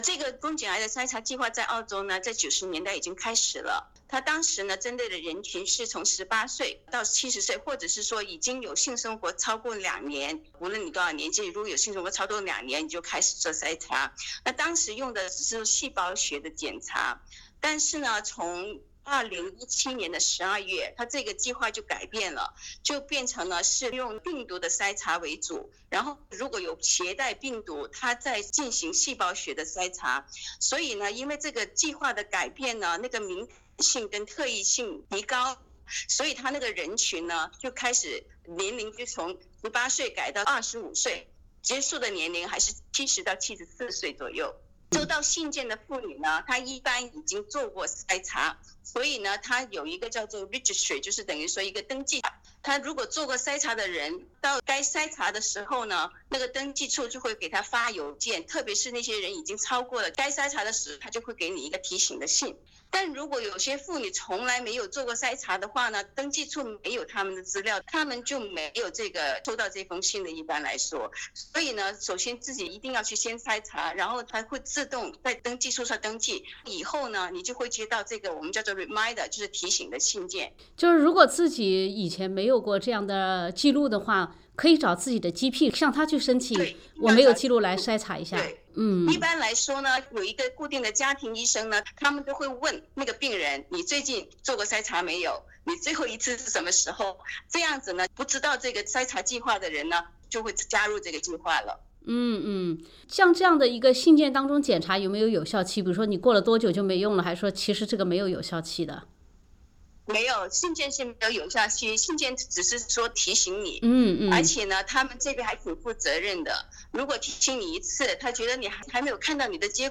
妇科专家一一解答。
想要了解更多实用信息，欢迎收听详细采访内容。